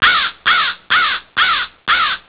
Crow
CROW.wav